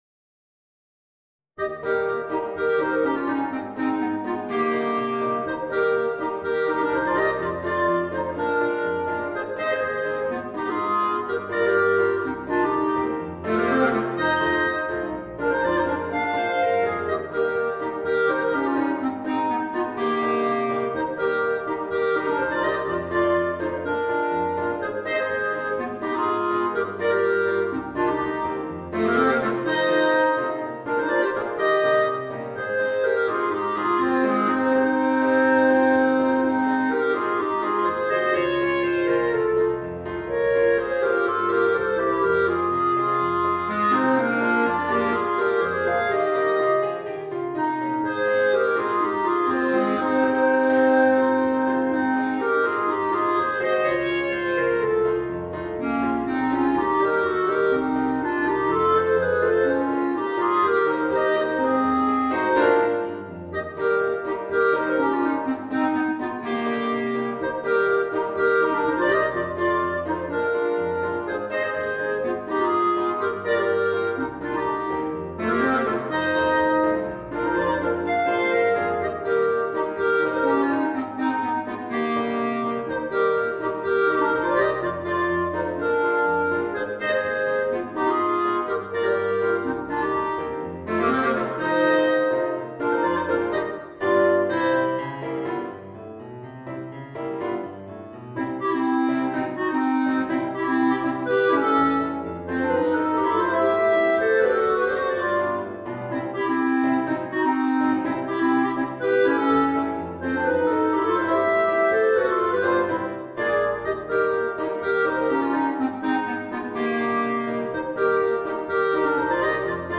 Voicing: 2 Clarinets w/ Audio